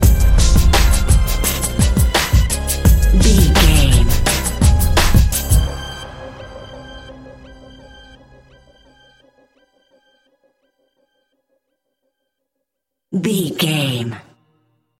Aeolian/Minor
D
drum machine
synthesiser
hip hop
soul
Funk
neo soul
acid jazz
energetic
cheerful
bouncy
Triumphant
funky